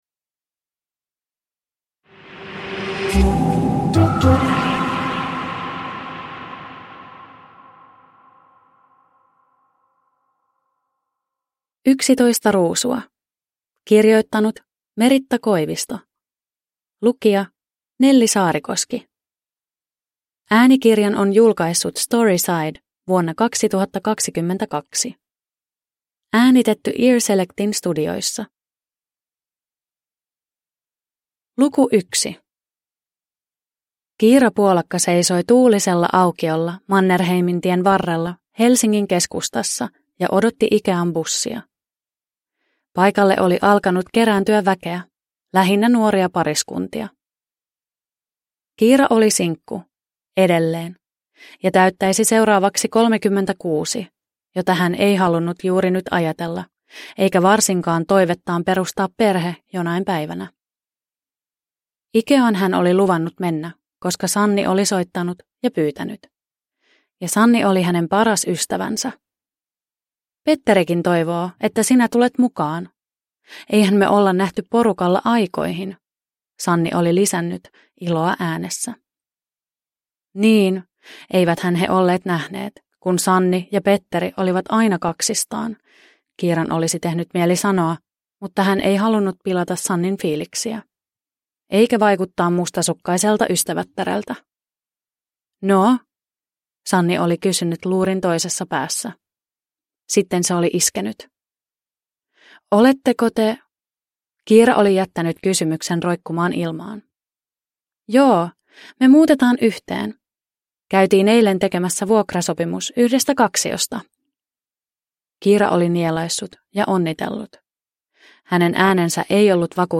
Yksitoista ruusua – Ljudbok – Laddas ner